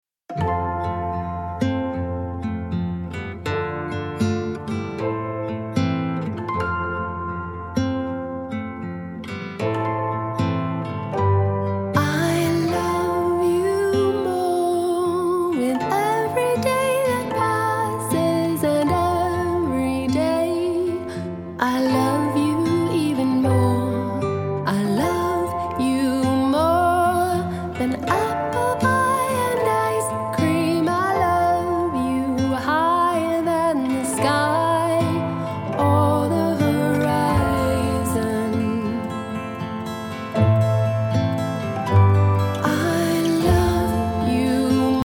mindful and quietly exultant music